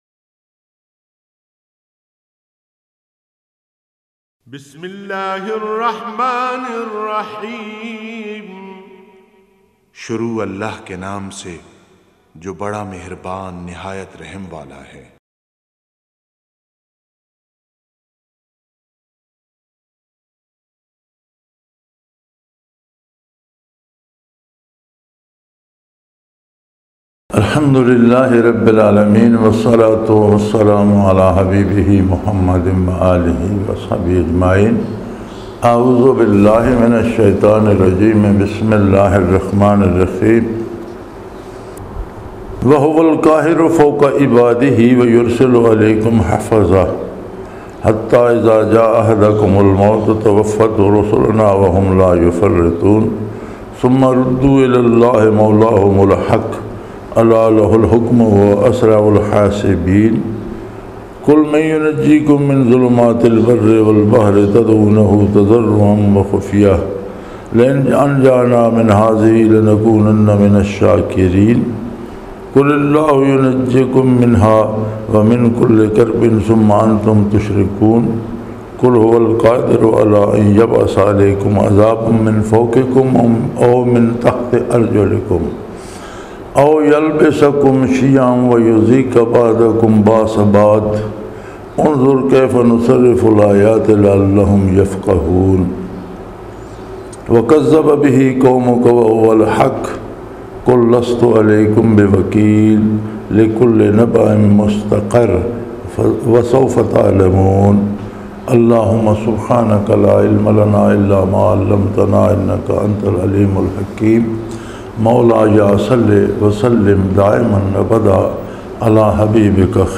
Punjabi Tafseer in Munara, Chakwal, Pakistan